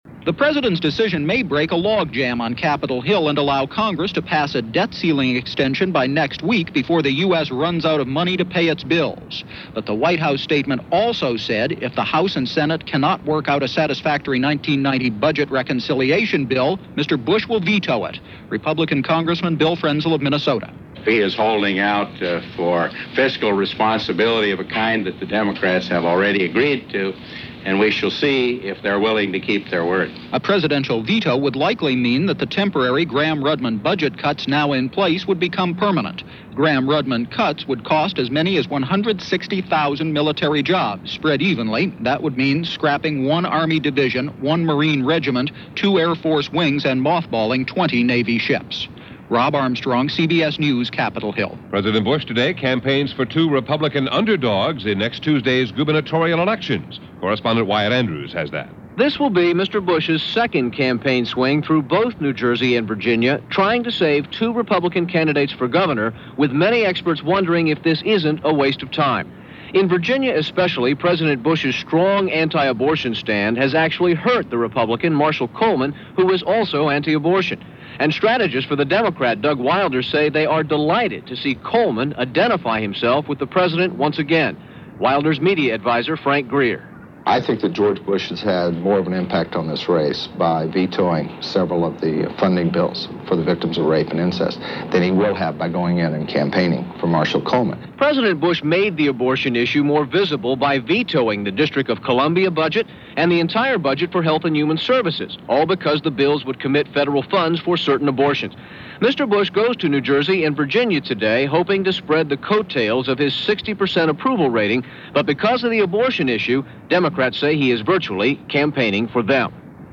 And that’s just a little of what happened on this day, during this week in November 1989, as presented by The CBS World News Roundup.